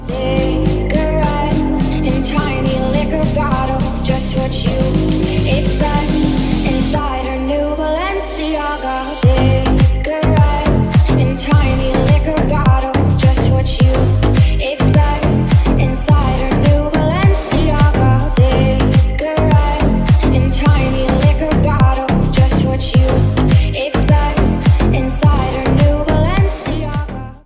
catchy and trendy song